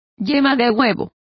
Complete with pronunciation of the translation of yolks.